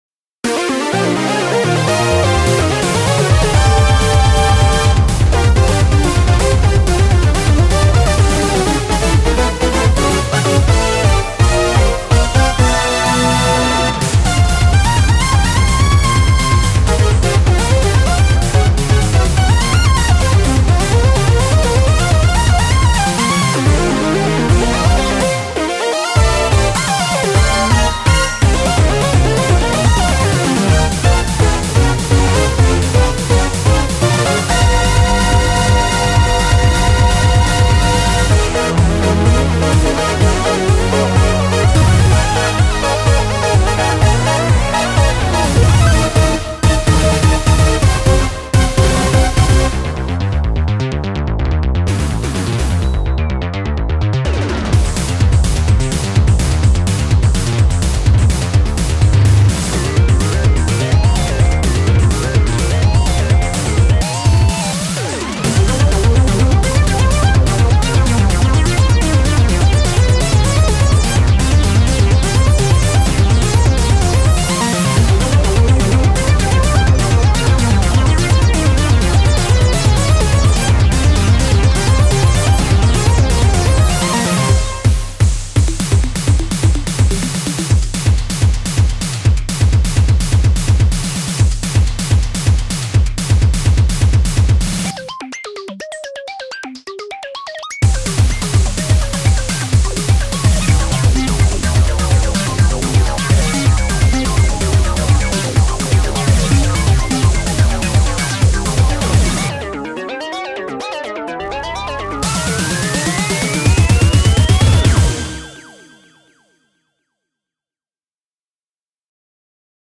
BPM252
Comments[SYNTHETIC PROGRESSIVE ROCK]